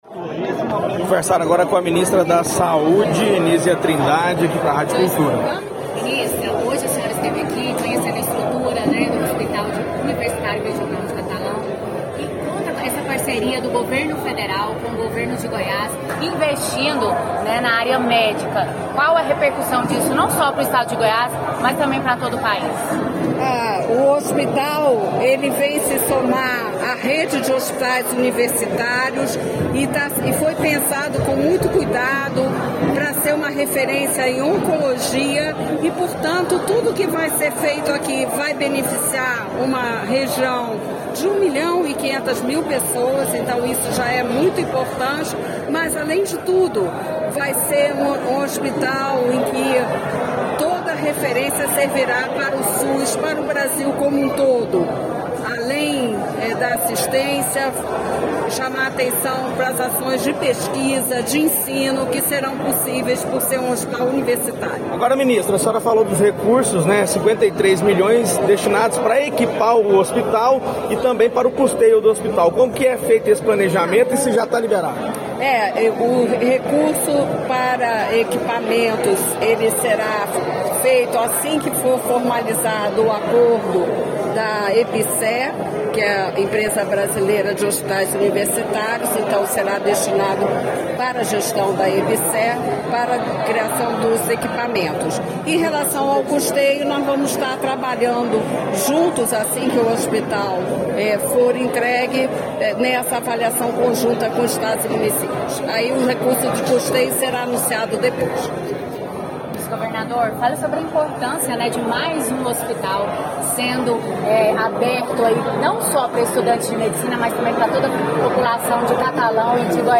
Autoridades durante coletiva de imprensa.
Ouça as entrevistas da Ministra Nísia Trindade, do vice-governador de Goiás, Daniel Vilela, prefeito Adib Elias, deputado federal José Nelto, e o deputado estadual, Jamil Calife: